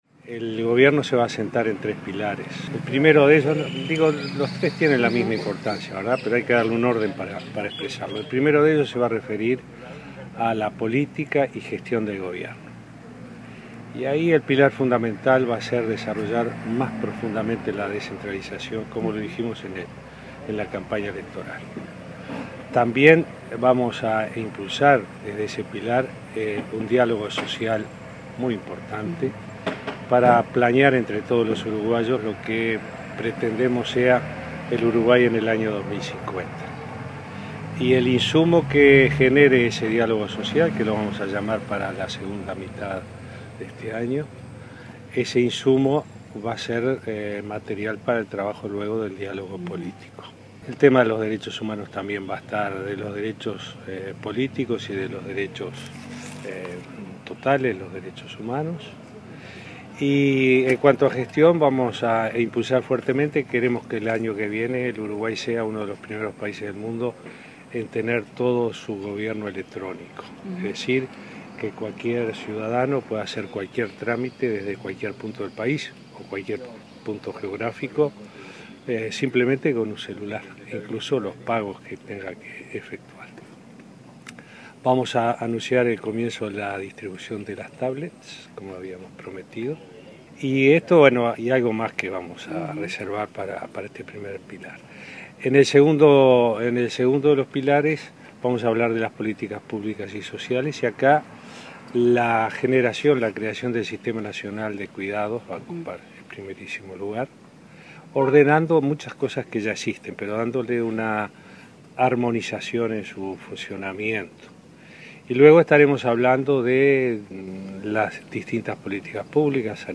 Al salir de su domicilio rumbo al Palacio Legislativo, Vázquez adelantó a la prensa algunos puntos de lo que hablará esta noche en cadena nacional.